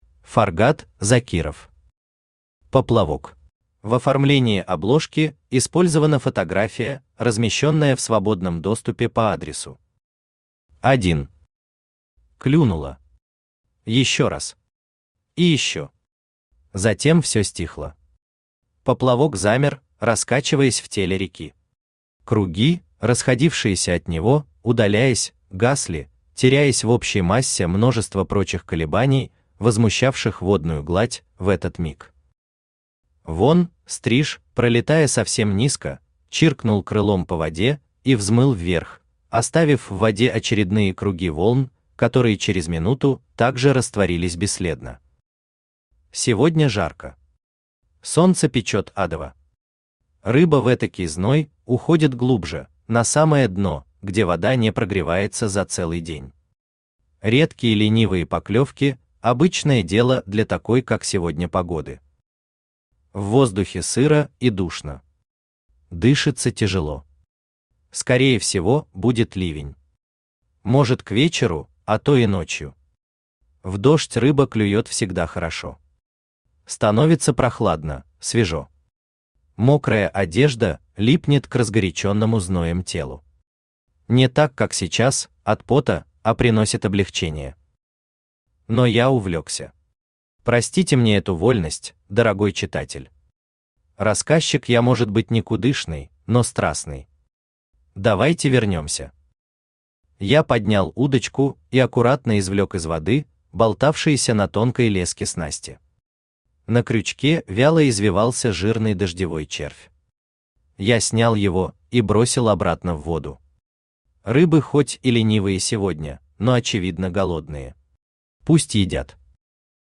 Aудиокнига Поплавок Автор Фаргат Закиров Читает аудиокнигу Авточтец ЛитРес.